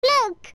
-A bell button, of course.
OOT_Navi_Look.wav